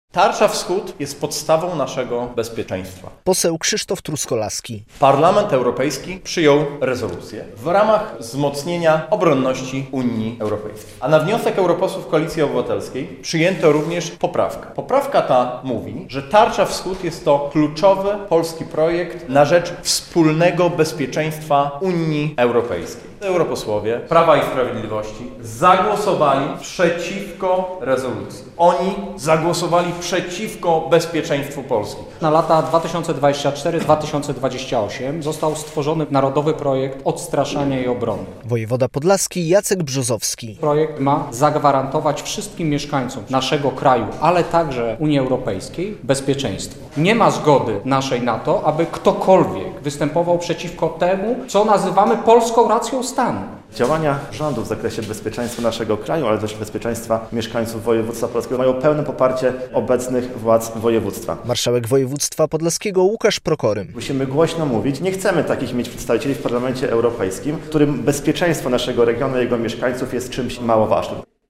Bezpieczeństwo Polaków jest priorytetem, a projekt Tarcza Wschód ma je wzmocnić. Podkreślali to politycy Platformy Obywatelskiej na wtorkowej (18.03) konferencji prasowej w Białymstoku.